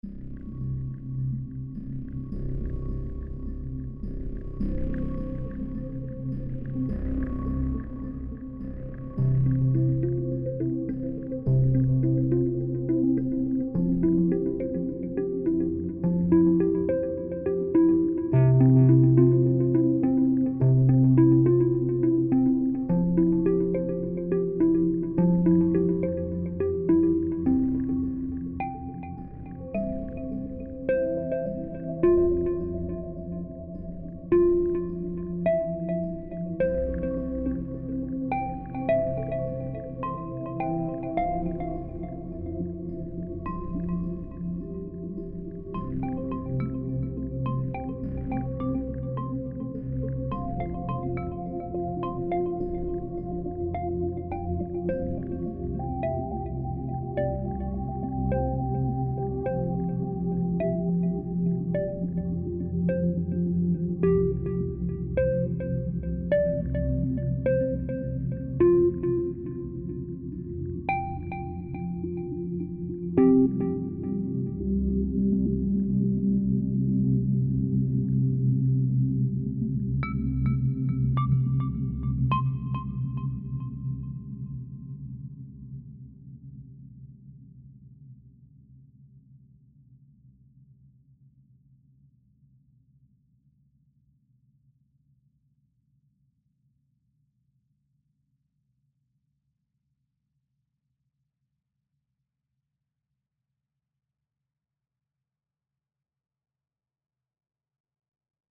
Credits music